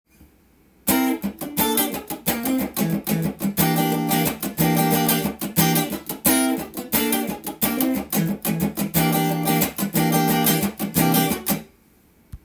パターン②は、テンションコードや１弦にトップノートを
持ってきたC7とカッティング奏法をミックスした形になっています。